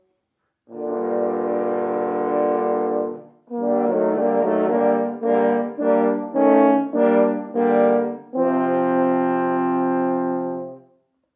MBew3qaxxIi_Trompas.mp3